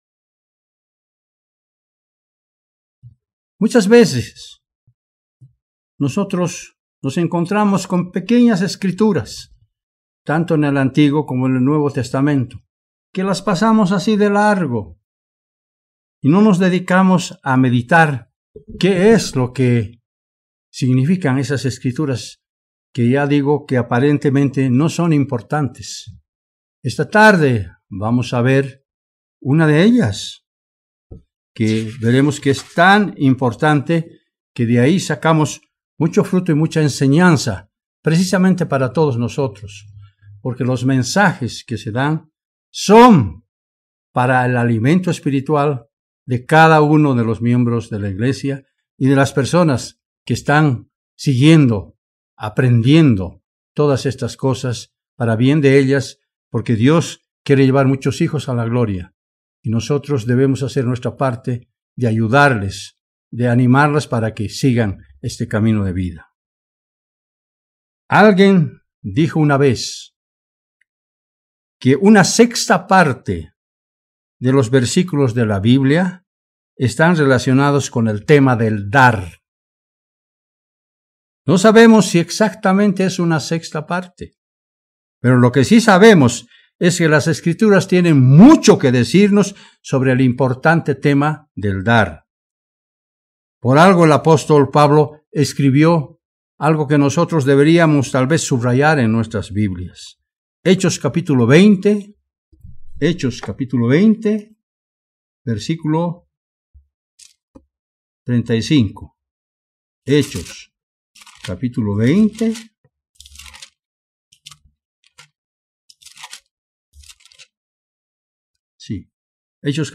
Sermones
Given in La Paz